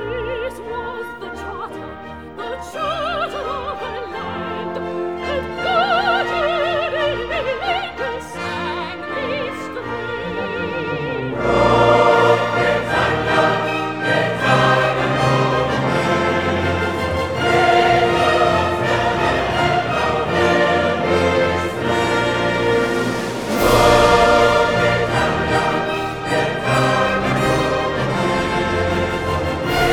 • Classical